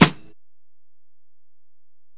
Snare (21).wav